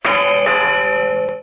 scaricare qui il file mp3 con il suono della campana dell'orologio
dindon.mp3